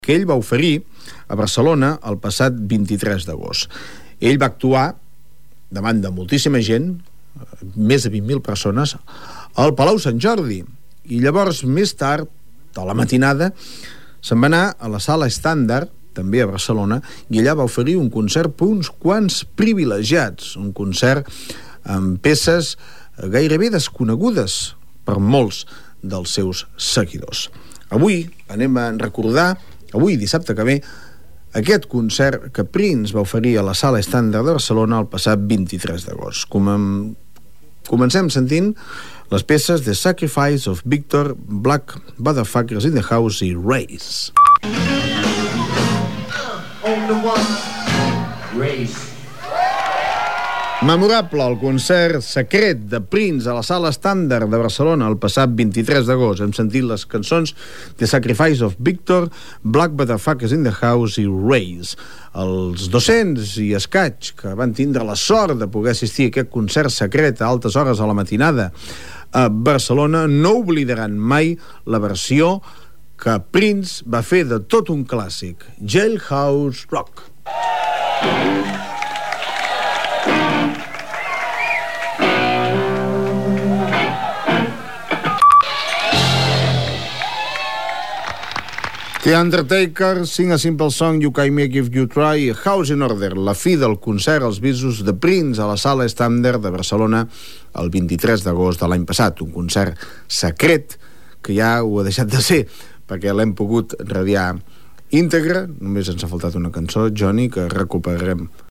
Crònica del concert que Prince va oferir el 23 d'agost de 1993 a la sala Estàndard de Barcelona.
Musical